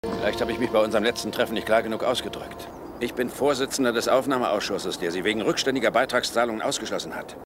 Efrem Zimbalist jr.: Inspektor Lewis Erskine,  Dubbing actor: Hartmut Reck
Sound file of German dubbing actor (117 Kb)